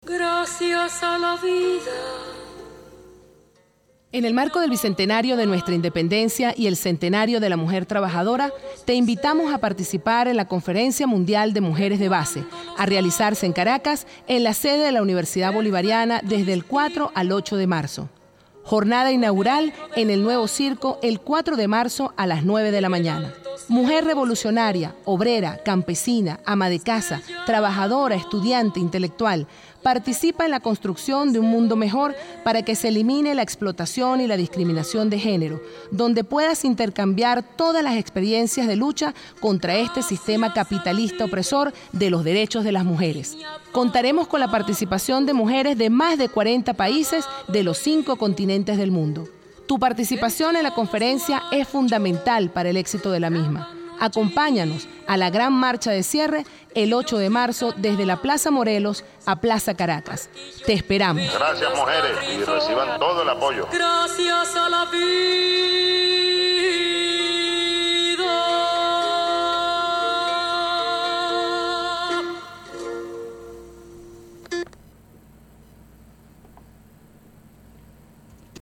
Micro radial para la difusión de la Conferencia de las Mujeres